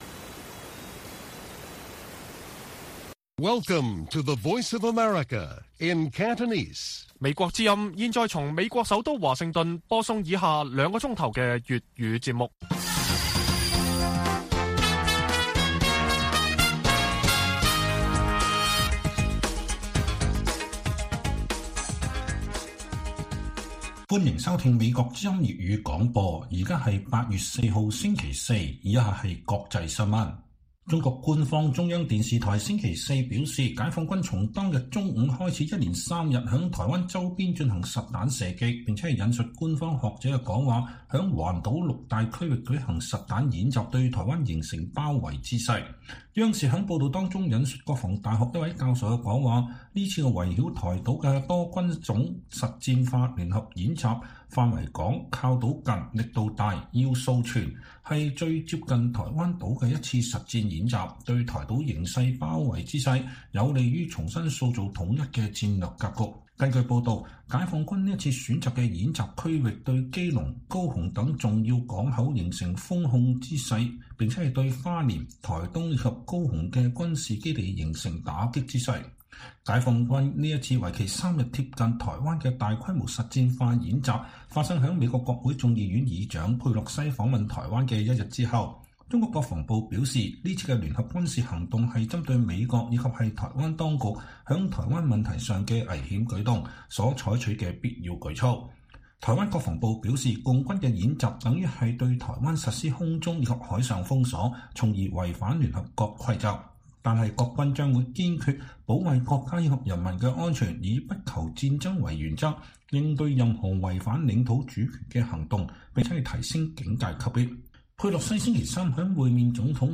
粵語新聞 晚上9-10點: 中國軍隊開始舉行“包圍”台灣的實戰化演習